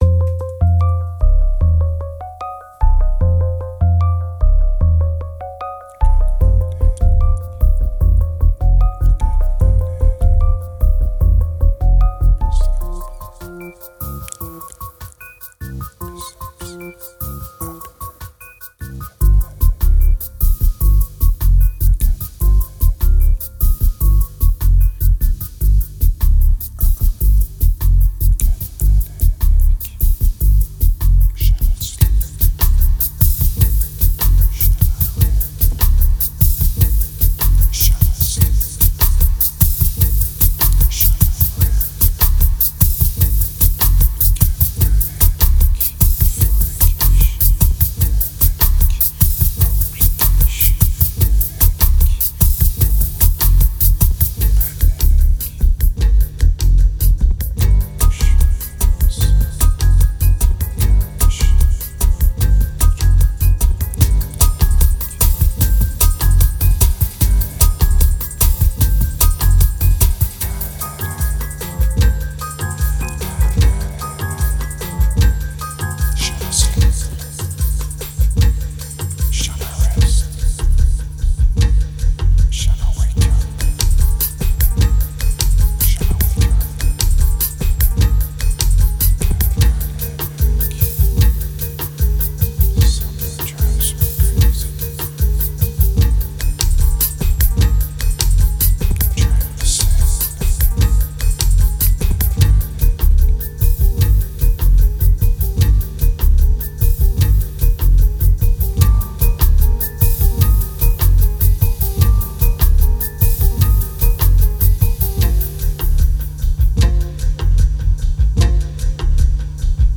2227📈 - -51%🤔 - 75BPM🔊 - 2010-03-01📅 - -340🌟